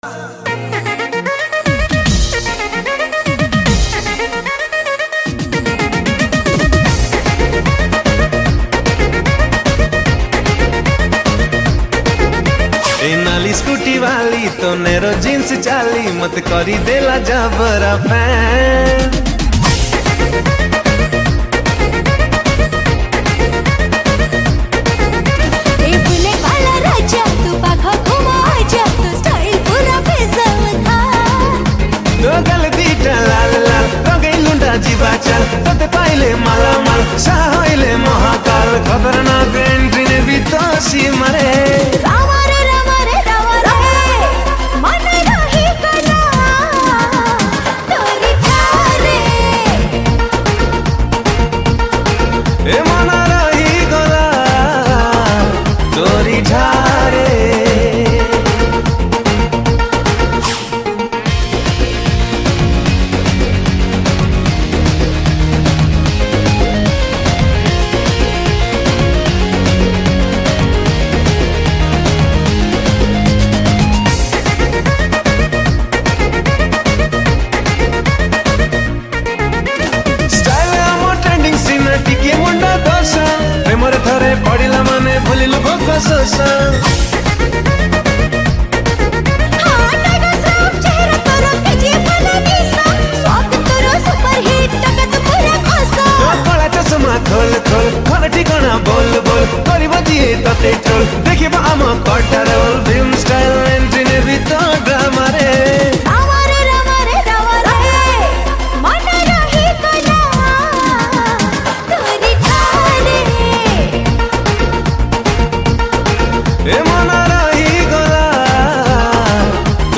Best Dance Dhamaka Song